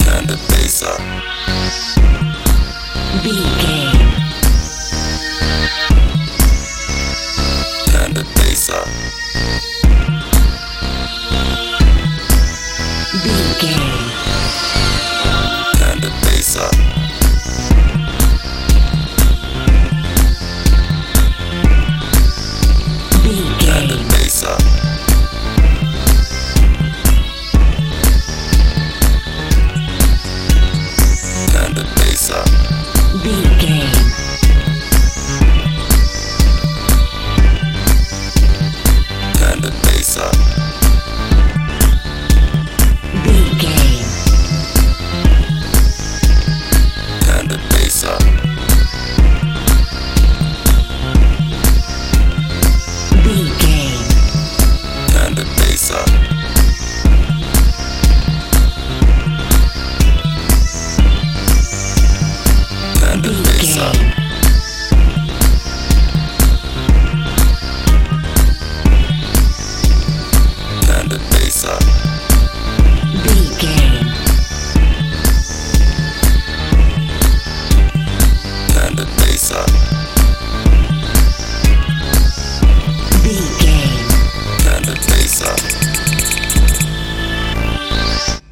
dance feel
Ionian/Major
F♯
magical
strange
synthesiser
bass guitar
drums
80s
90s